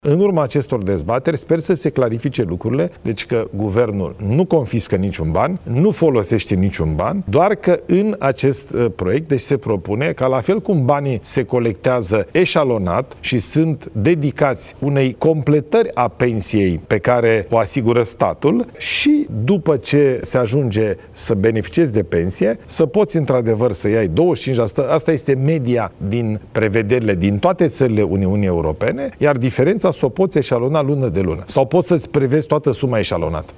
Guvernul nu confiscă niciun ban din pilonul II de pensii, spune premierul Ilie Bolojan. Invitat la Antena 3, prim-ministrul a spus că proiectul de lege privind pensiile private urmează modelul aplicat în țările europene și că România trebuie să facă aceste schimbări legislative pentru a îndeplini jaloanele necesare aderării la OCDE –  Organizația pentru Cooperare și Dezvoltare Economică.
11aug-10-Bolojan-despre-pilonul-II-de-pensii.mp3